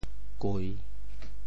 瑰（瓌） 部首拼音 部首 王 总笔划 13 部外笔划 9 普通话 guī 潮州发音 潮州 gui3 文 中文解释 瑰 <名> (形声。